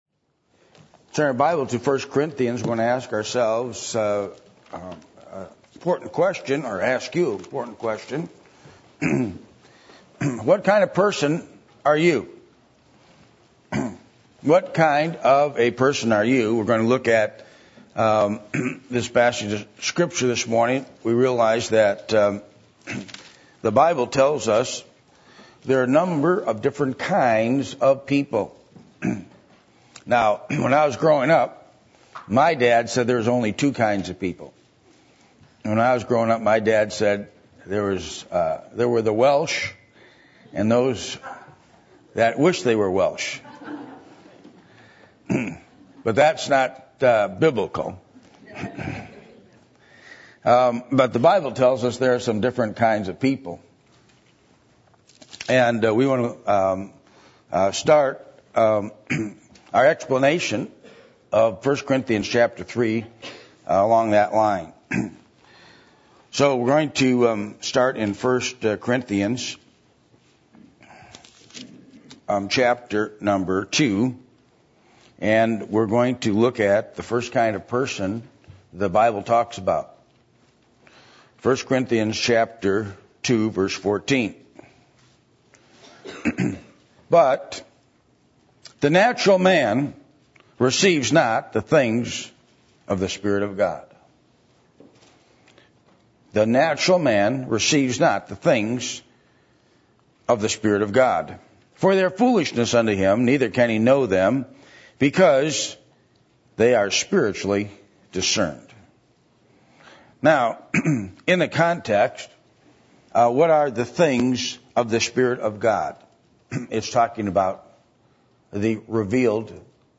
Passage: 1 Corinthians 3:1-15 Service Type: Sunday Morning %todo_render% « The Branch Cannot Bear Fruit Of Itself What Will The Coming Of Christ Be For You?